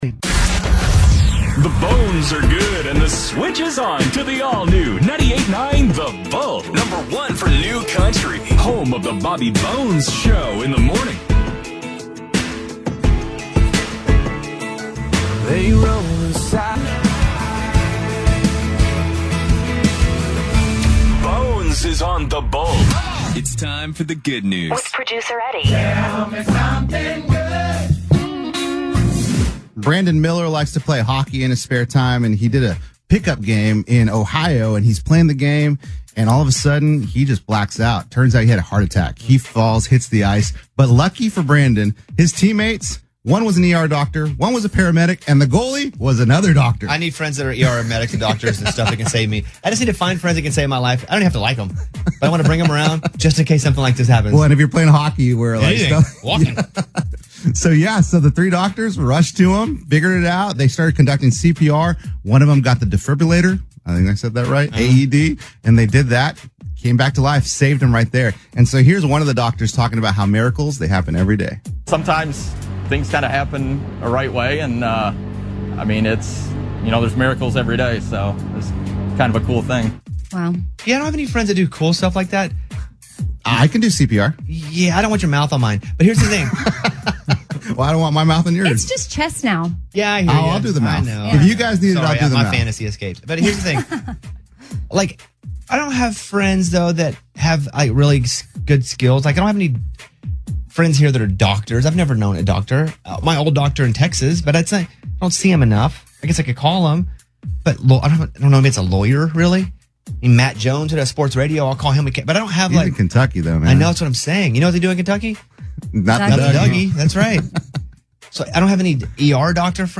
Previous Format: Country “98.9 The Bull” KNUC
New Format: AAA 98.9 KPNW-FM